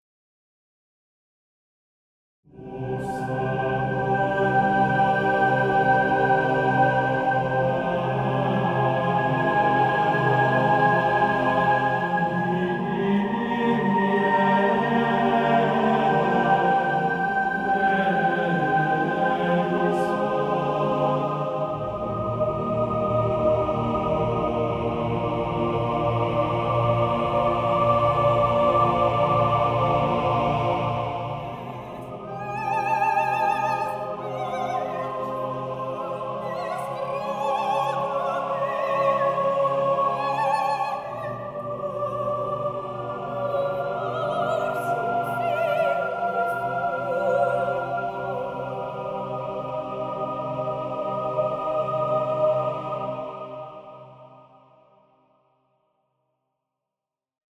ça pour test les choeurs rapidement).
Il sonne bien ton test de voix.
TestChoeurs.mp3